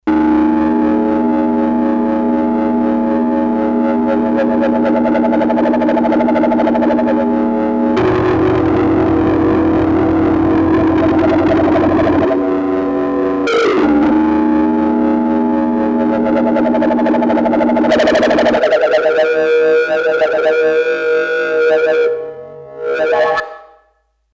Virtual Analog Synthesizer
M-Audio Venom single patch demos